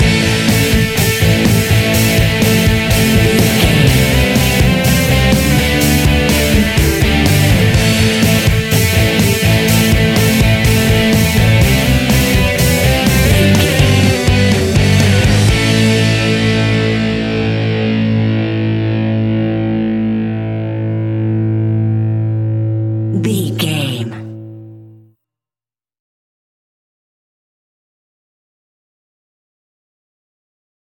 Epic / Action
Fast paced
Aeolian/Minor
hard rock
heavy metal
blues rock
distortion
rock guitars
Rock Bass
heavy drums
distorted guitars
hammond organ